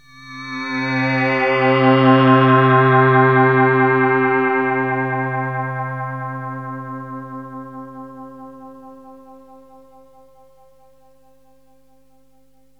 AMBIENT ATMOSPHERES-1 0005.wav